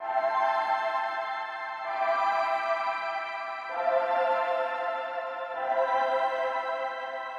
天使之声
描述：只是合唱团的和弦，很基本...
标签： 130 bpm Trap Loops Vocal Loops 1.24 MB wav Key : E
声道立体声